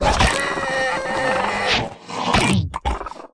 Npc Raccoon Eat Sound Effect
npc-raccoon-eat.mp3